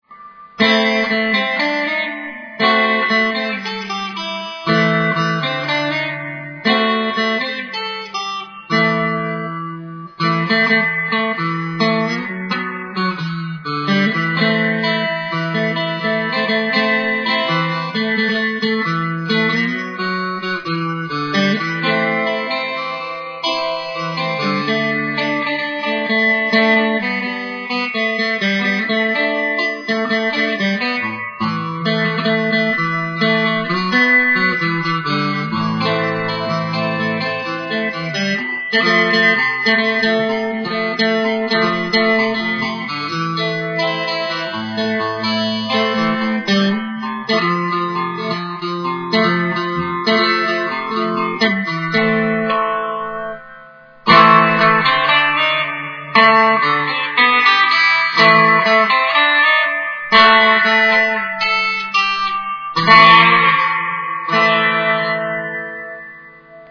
Sample avec ampli VOX AC 15